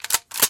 人体 " 膝关节噼啪作响
描述：用15厘米以外的Zoom H1录制噼啪作响的膝盖。附近有一个冰箱，所以录音中有一些噪音/嗡嗡声。对于那个很抱歉。
标签： 噼啪作响 膝盖 骨骼 开裂 关节 人体
声道立体声